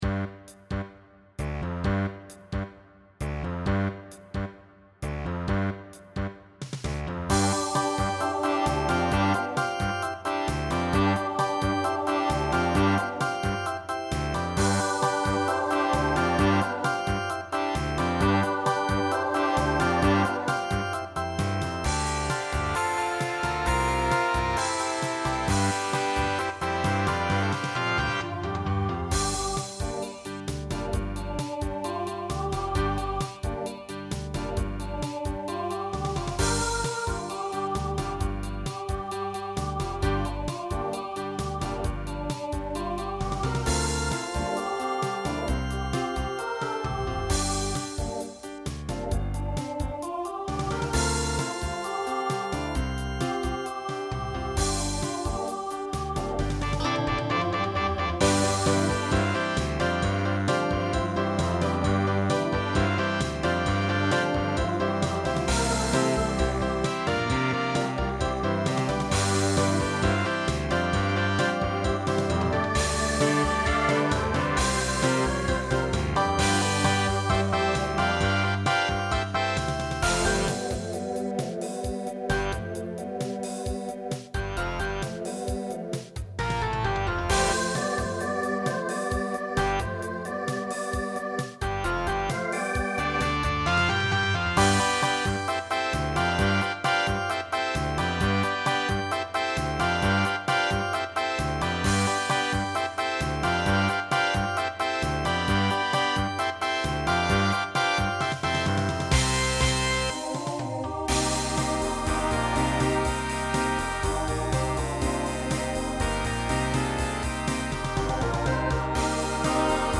TTB/SSA
Voicing Mixed Instrumental combo Genre Pop/Dance , Rock